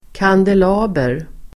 Ladda ner uttalet
kandelaber substantiv, candelabra Uttal: [kandel'a:ber] Böjningar: kandelabern, kandelabrar Synonymer: ljusstake Definition: flerarmad ljusstake Sammansättningar: silverkandelaber (silver candelabra)